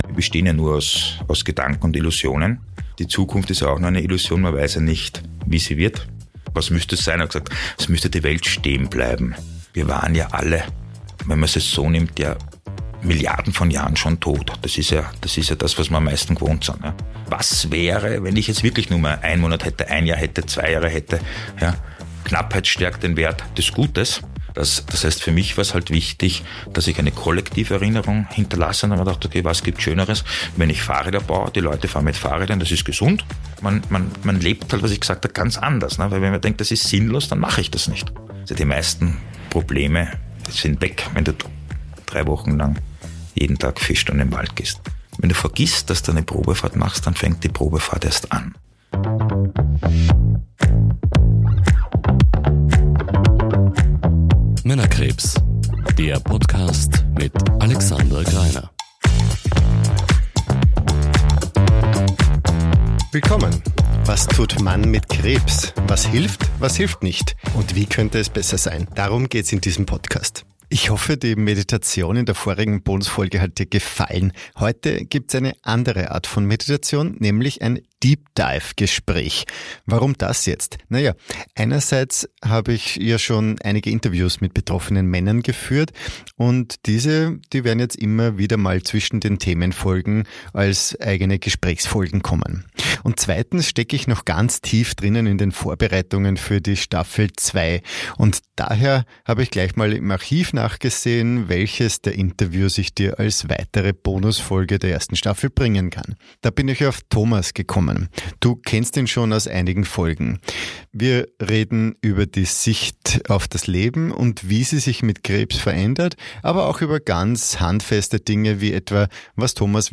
Gespräch) · Folge 8 ~ Männerkrebs – Was tut Mann mit Krebs?